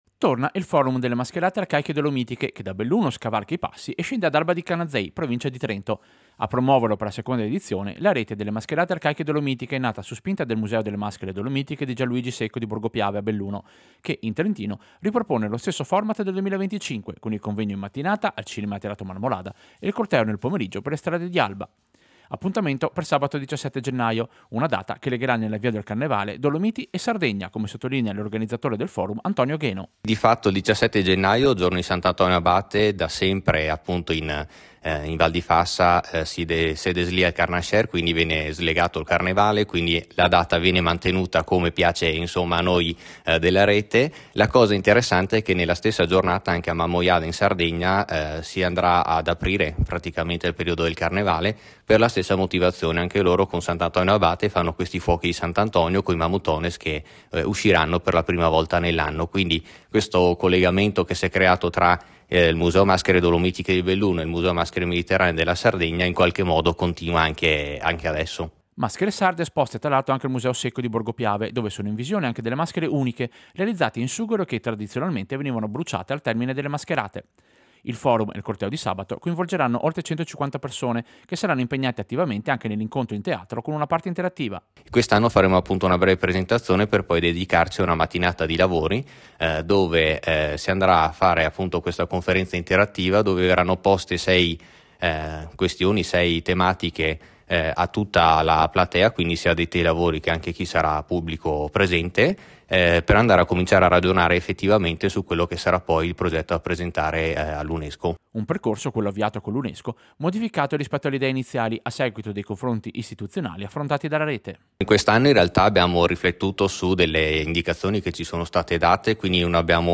Servizio-Forum-maschere-Canazei.mp3